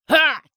CK格挡03.wav
CK格挡03.wav 0:00.00 0:00.57 CK格挡03.wav WAV · 49 KB · 單聲道 (1ch) 下载文件 本站所有音效均采用 CC0 授权 ，可免费用于商业与个人项目，无需署名。
人声采集素材/男2刺客型/CK格挡03.wav